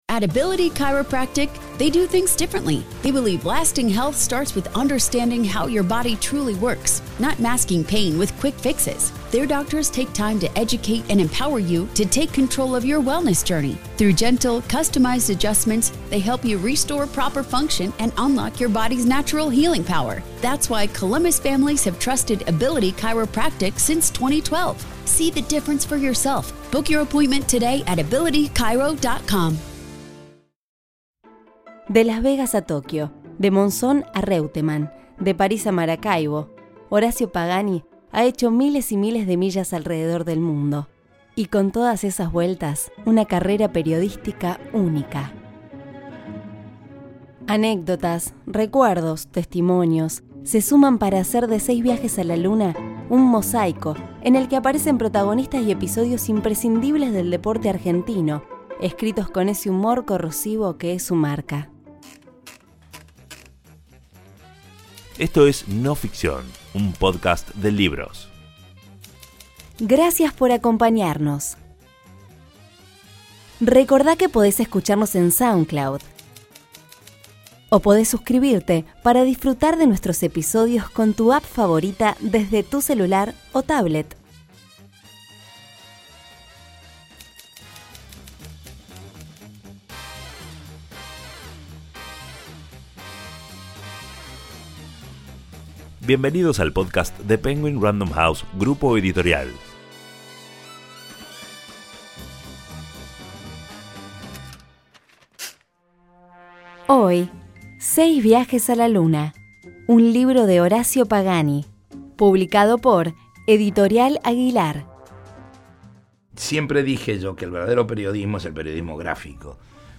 Entrevistado: Horacio Pagani Narradora